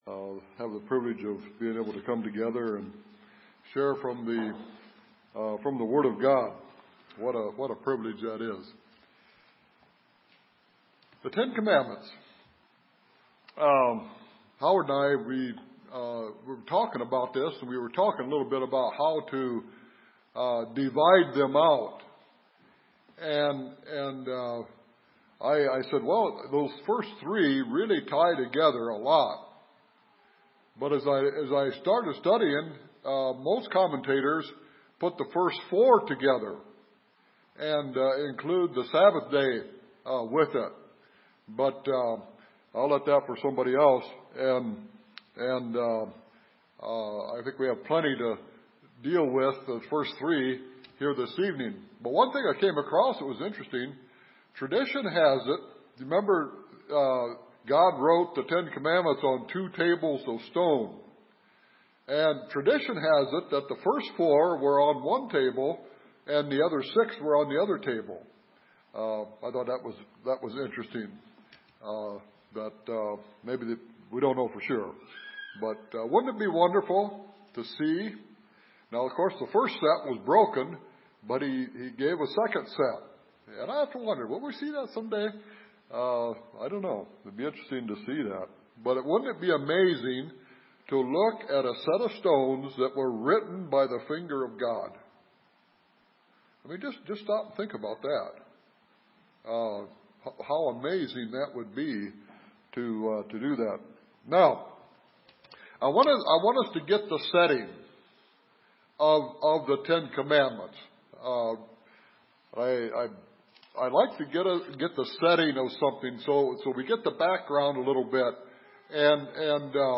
Like this sermon?